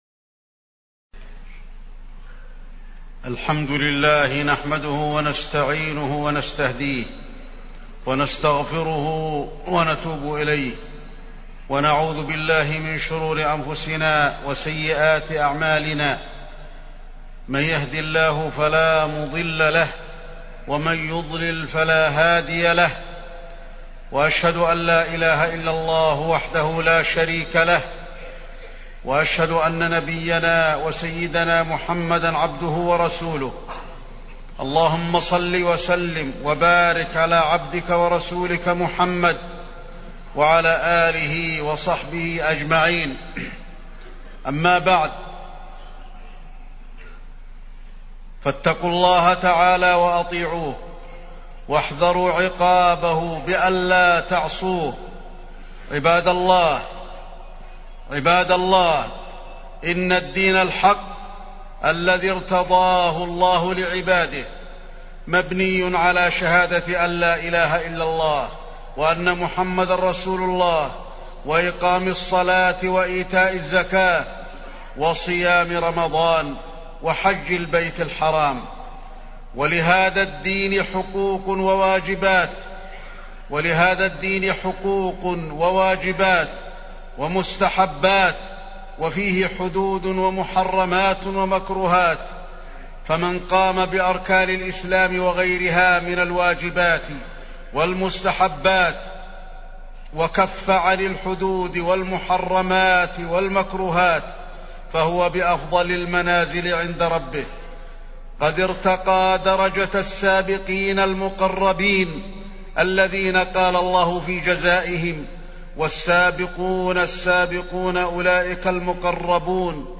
تاريخ النشر ١٤ محرم ١٤٢٨ هـ المكان: المسجد النبوي الشيخ: فضيلة الشيخ د. علي بن عبدالرحمن الحذيفي فضيلة الشيخ د. علي بن عبدالرحمن الحذيفي ليس الإسلام بالإدعاء